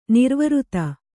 ♪ nirvřta